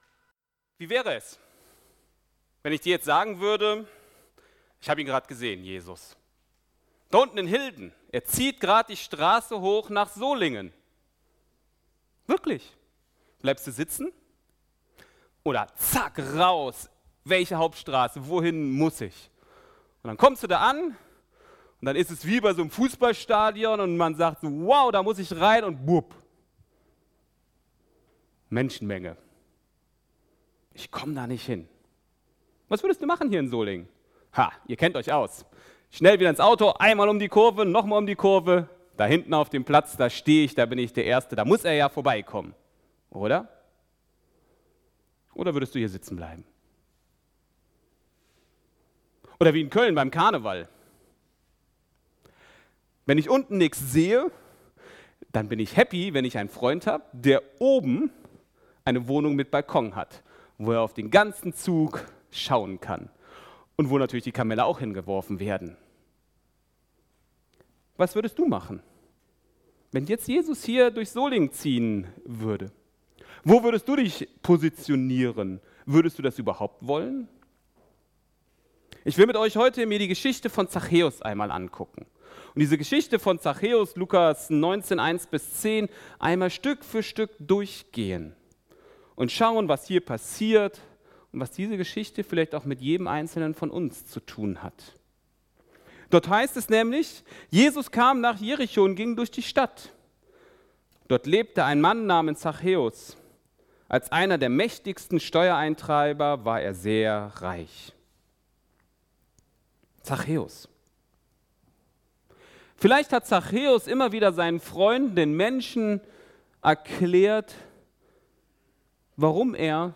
2024 in Gottesdienst Keine Kommentare 219 WATCH LISTEN